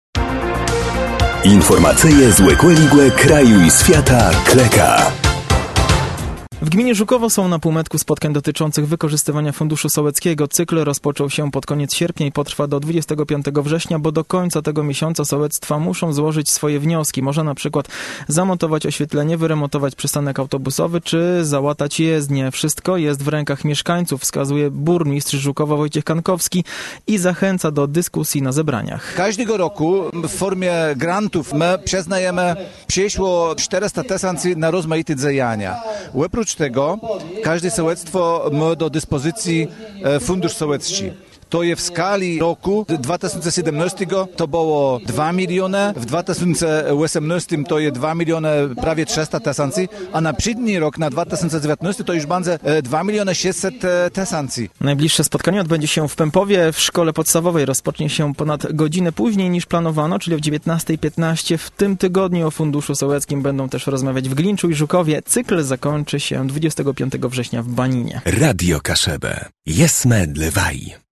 – Wszystko jest w rękach mieszkańców – wskazuje burmistrz Żukowa Wojciech Kankowski i zachęca do dyskusji na zebraniach.